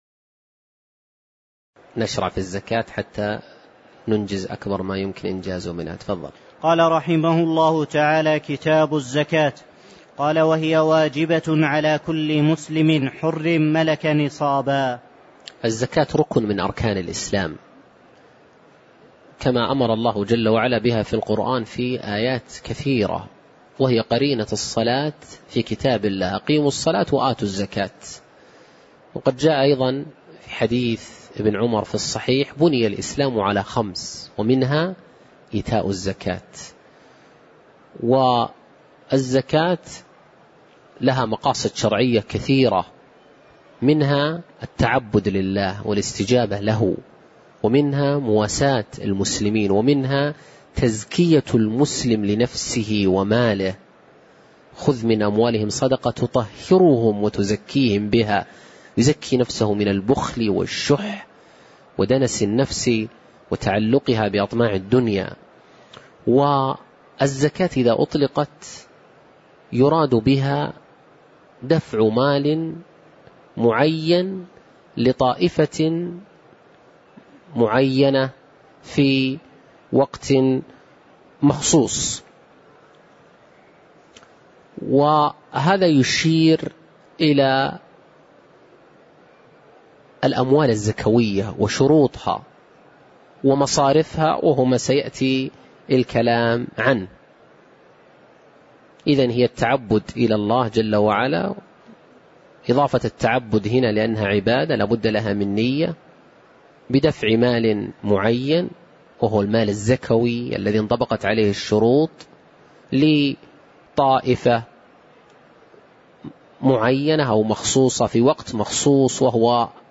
تاريخ النشر ١٦ شوال ١٤٣٧ هـ المكان: المسجد النبوي الشيخ